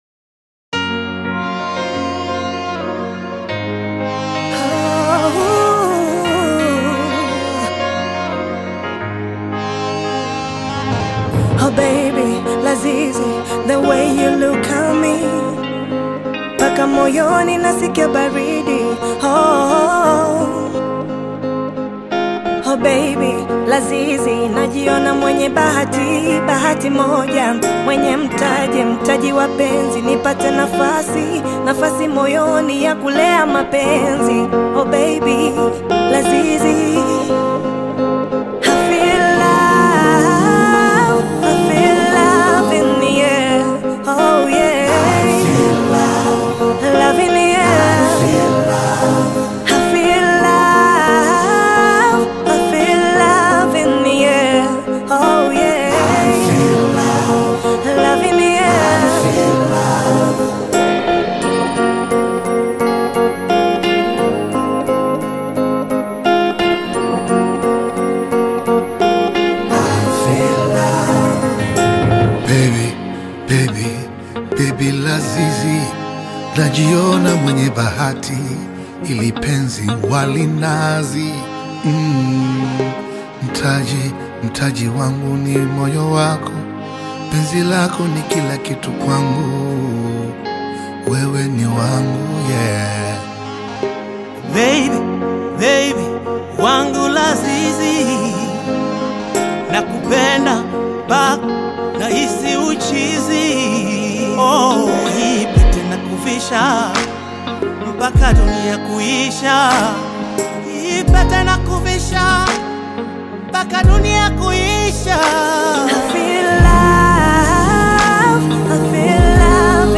heartfelt Afro-inspired single